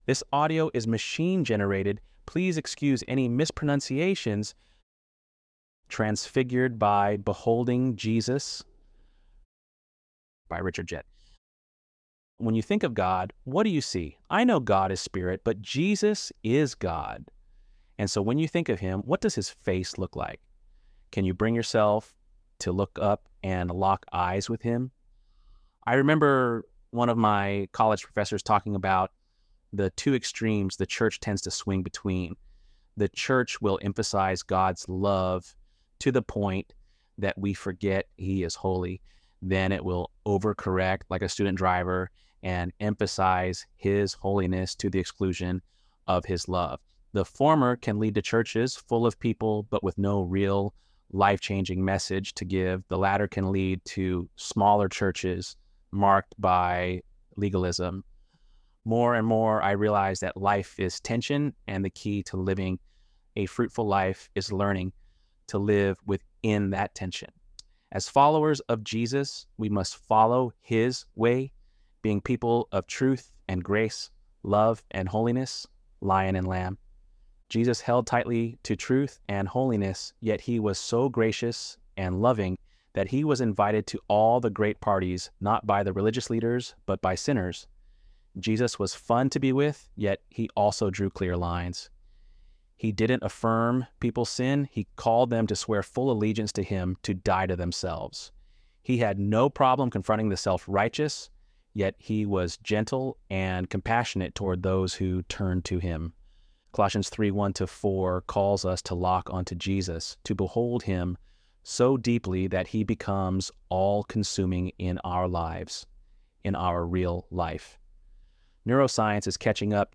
ElevenLabs_2_4.mp3